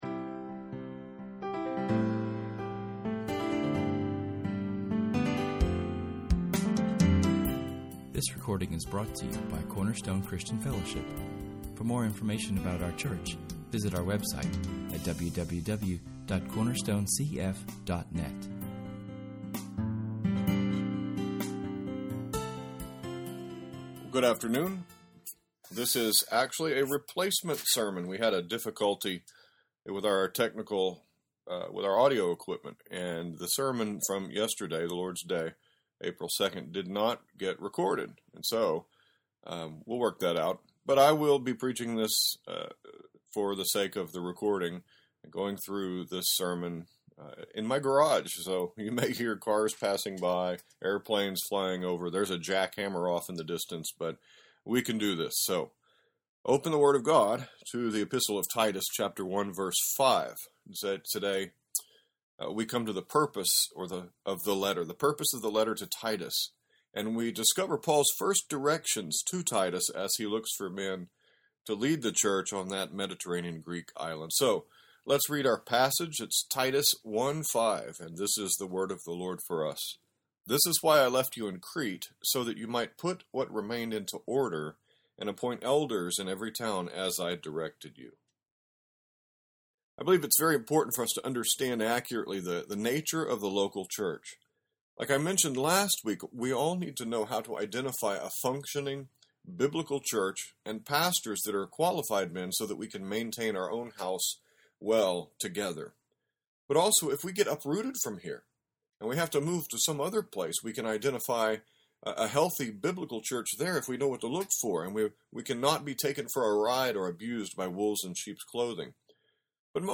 Our sermon this week is entitled “Put Into Order.”
This is the same sermon, preached Monday morning.